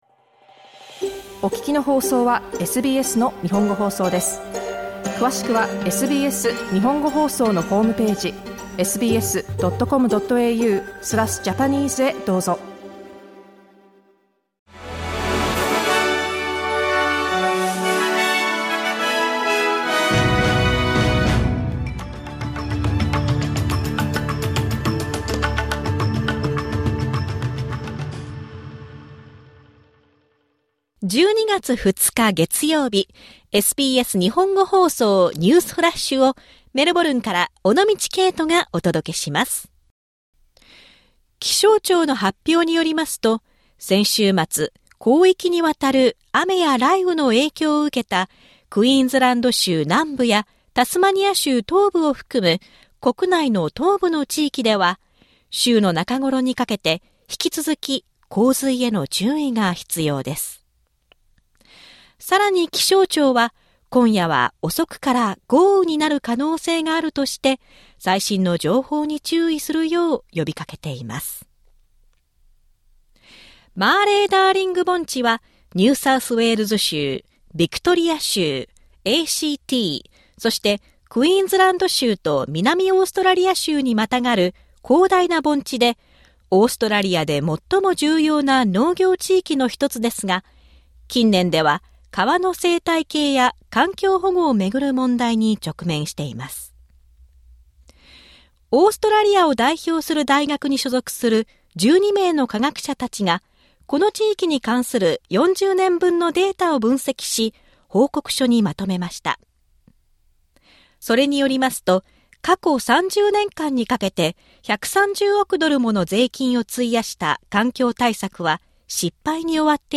SBS日本語放送ニュースフラッシュ 12月2日 月曜日